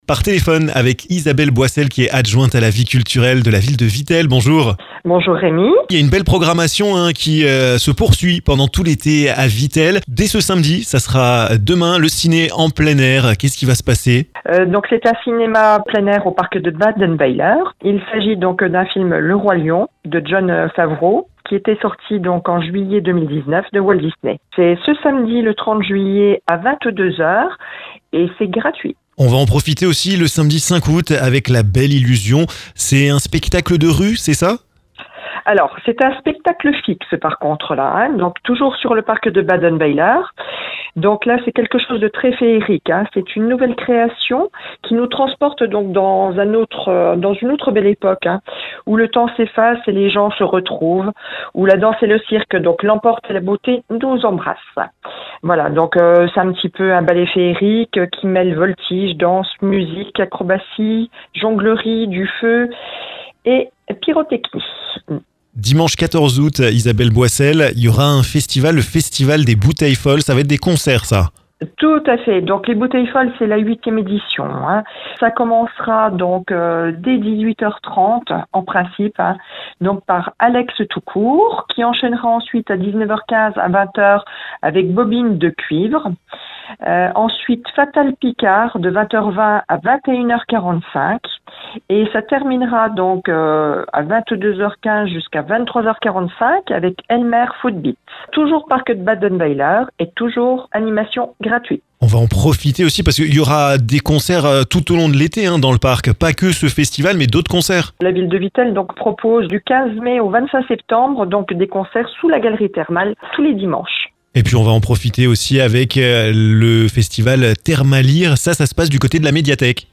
Cinéma en plein air, festivals, concerts, médiathèque, ... toute la programmation de l'été à Vittel est à retrouver dans ce podcast. Isabelle Boissel, adjointe à la vie culturelle de la ville de Vittel, a accepté de répondre à nos questions!